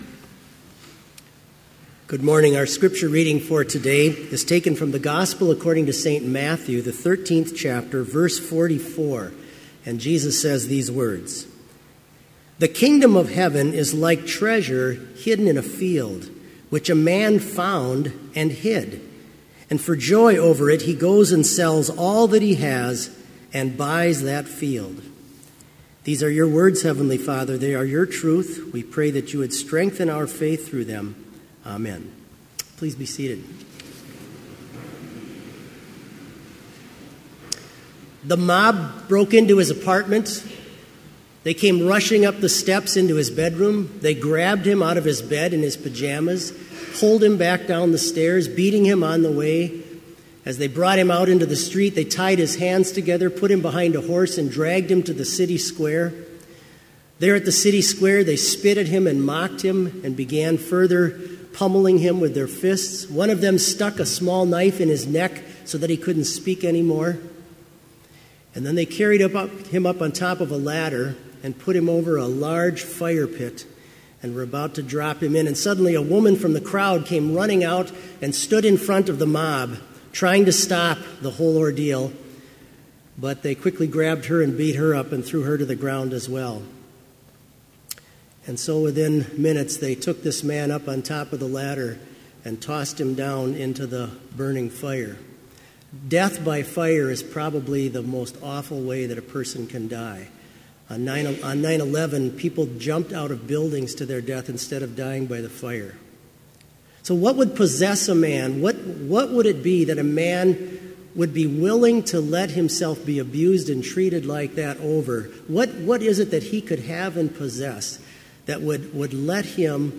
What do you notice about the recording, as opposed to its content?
This Chapel Service was held in Trinity Chapel at Bethany Lutheran College on Thursday, October 19, 2017, at 10 a.m. Page and hymn numbers are from the Evangelical Lutheran Hymnary.